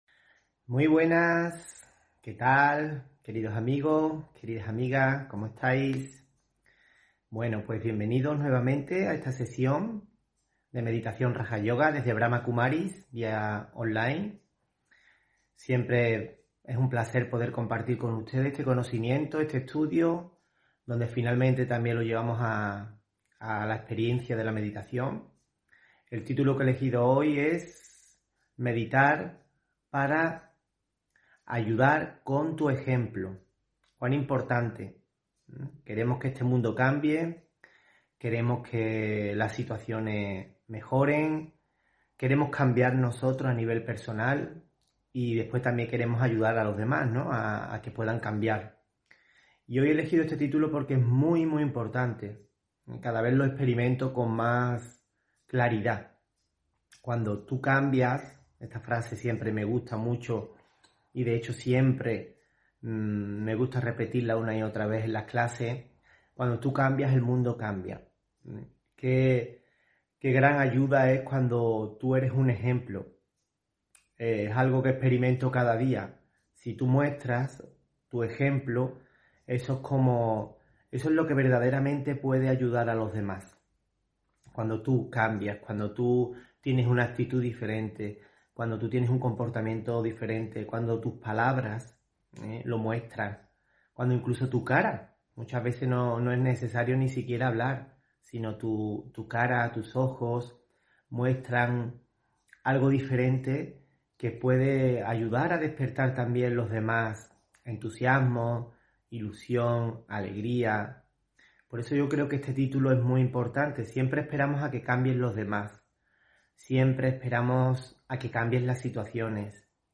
Meditación y conferencia: El poder de discernir (8 Noviembre 2022)